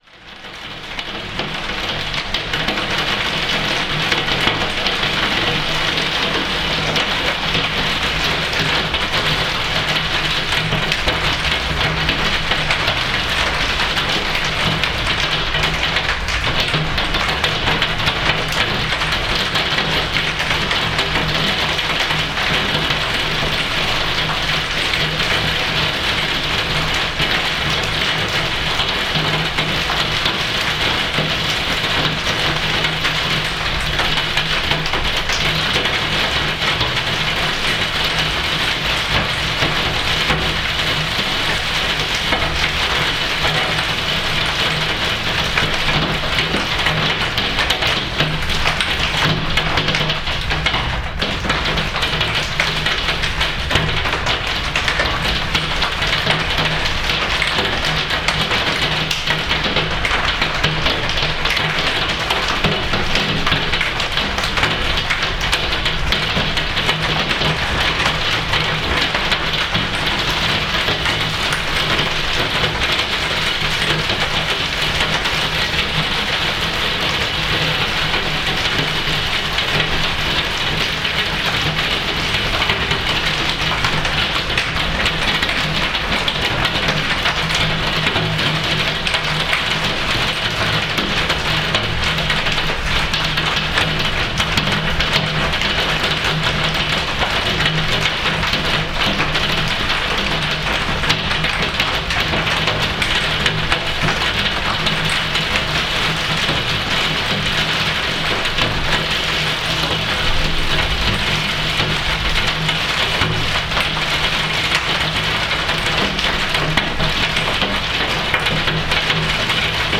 This is hail. The recording doesn't seem to accurately show just how loud this hail was. I was very far away from the window.